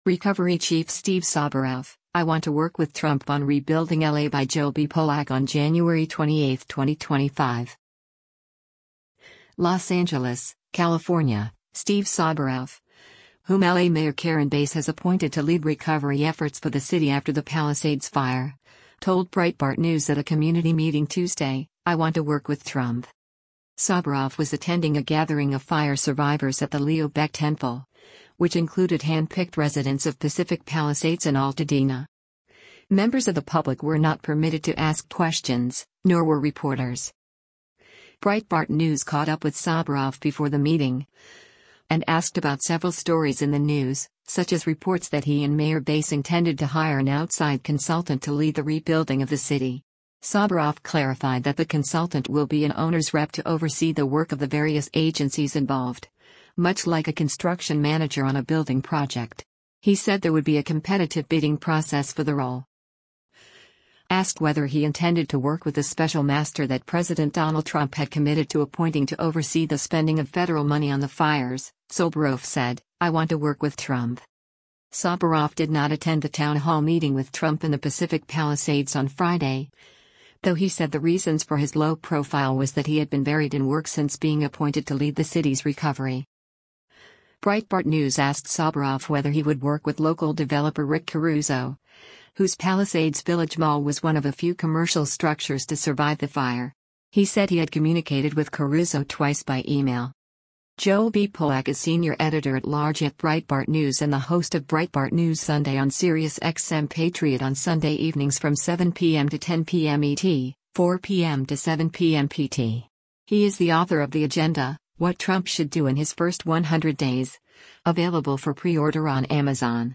Breitbart News caught up with Soboroff before the meeting, and asked about several stories in the news — such as reports that he and Mayor Bass intended to hire an “outside consultant” to lead the rebuilding of the city. Soboroff clarified that the “consultant” will be an “owner’s rep” to oversee the work of the various agencies involved, much like a construction manager on a building project.